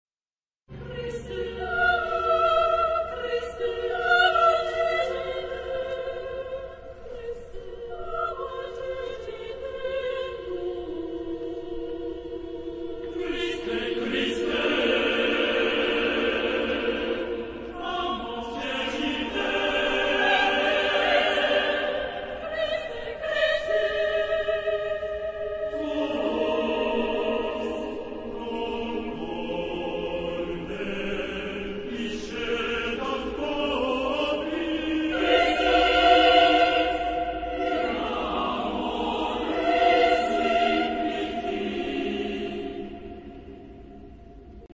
Type de matériel : Partition choeur seul
Genre-Style-Forme : Motet ; Sacré
Type de choeur : SATB (div.)  (7 voix mixtes )